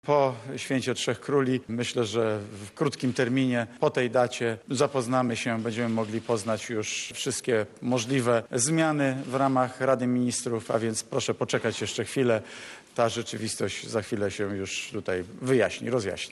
Podczas wspólnej konferencji prasowej z wicepremierem Jarosławem Gowinem szef Rady Ministrów zapowiedział powołanie instytutu badawczego w zakresie biotechnologii.